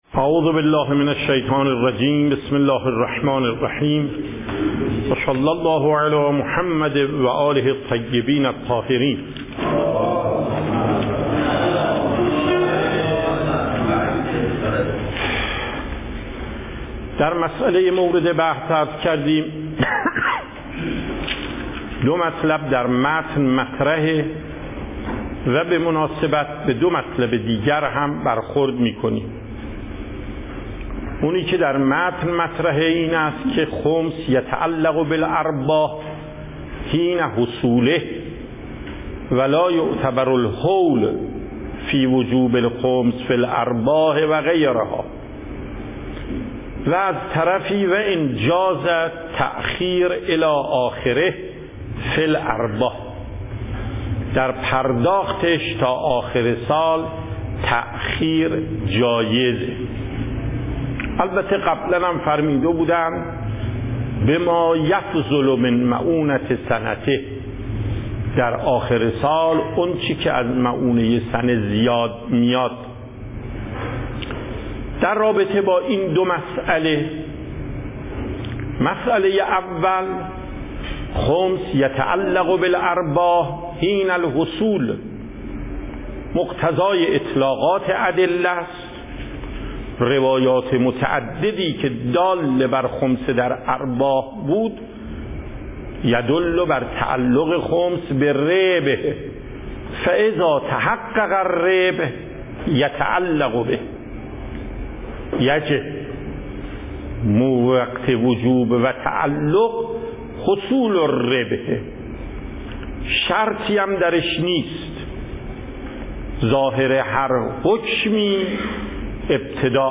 صوت و تقریر درس پخش صوت درس: متن تقریر درس: ↓↓↓ تقریری ثبت نشده است.